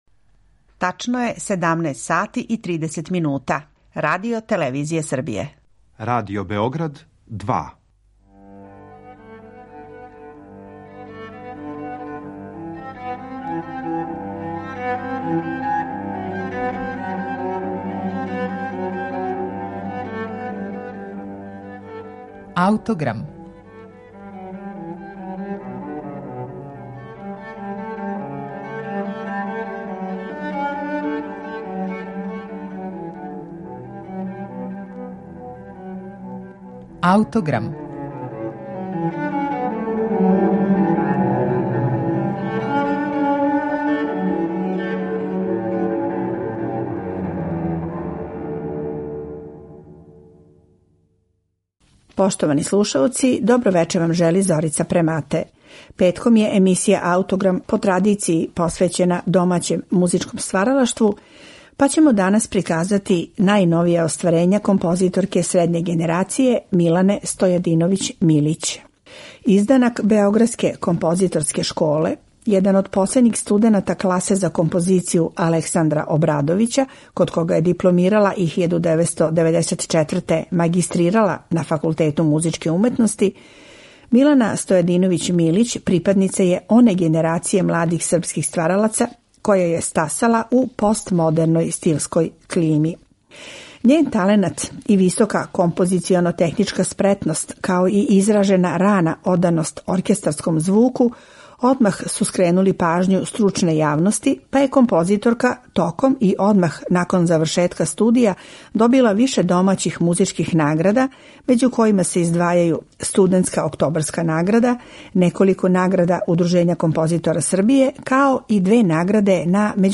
Портрет ће бити сагледан кроз њена најновија остварења, настала током последње деценије, а посебна пажња биће усмерена на вокални циклус „Сузе", који на нашем снимку изводе чланице камерног ансамбла „Donne di Belgrado", којима је ово дело и посвећено.